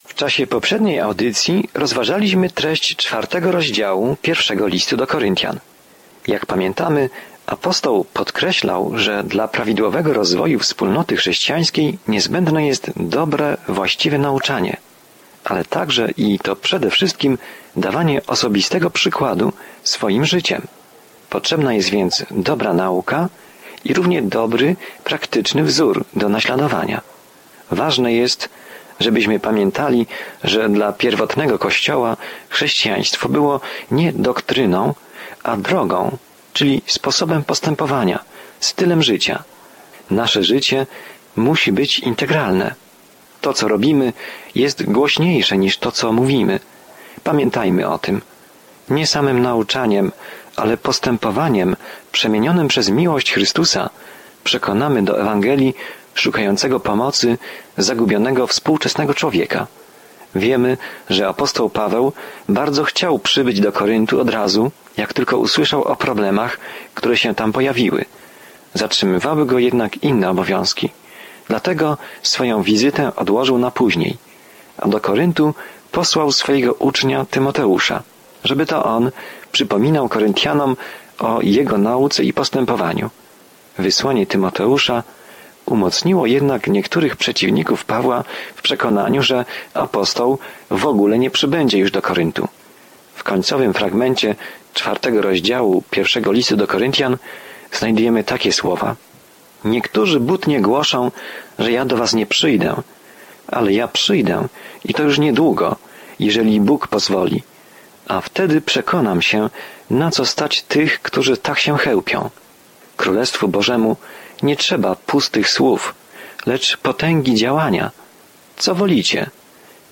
Jest to temat poruszony w Pierwszym Liście do Koryntian, zawierający praktyczną opiekę i korektę problemów, przed którymi stają młodzi chrześcijanie. Codziennie podróżuj przez 1 List do Koryntian, słuchając studium audio i czytając wybrane wersety słowa Bożego.